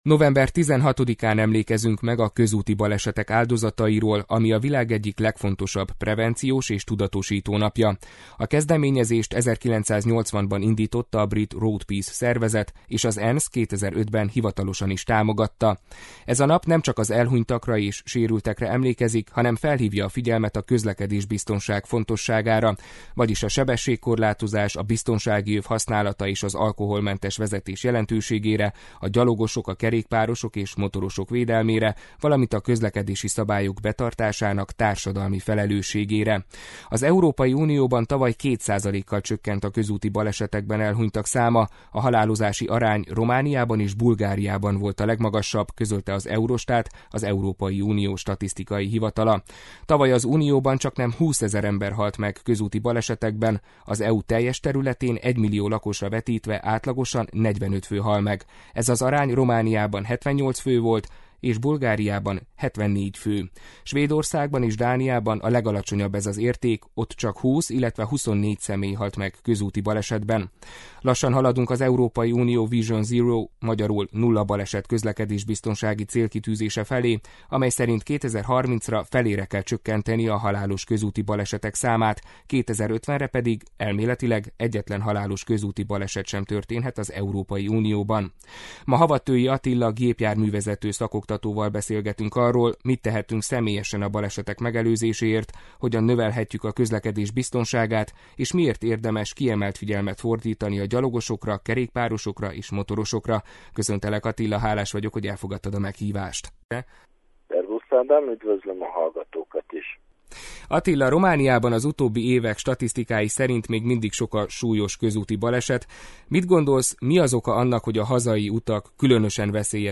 gépjárművezető szakoktatóval beszélgettünk